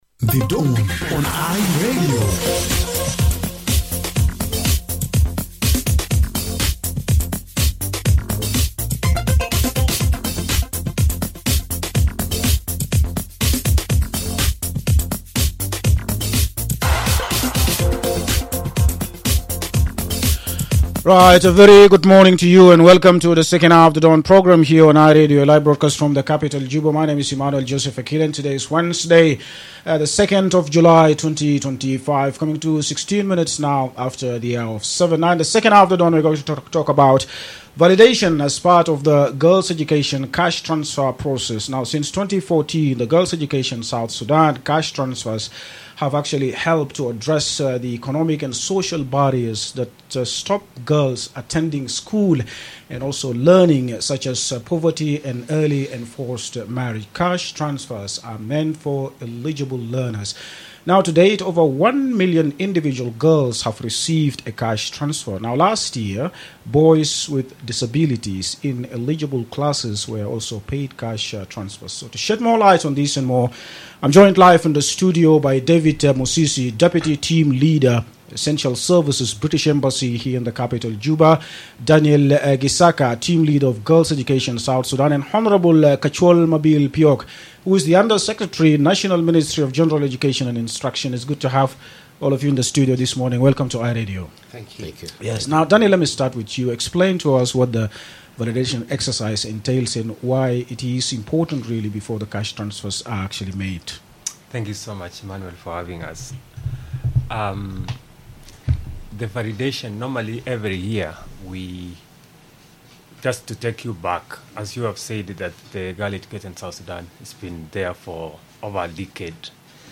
A selection of radio programmes can be found below.
GESS-Valiation-Exercise-on-Eye-Radio.mp3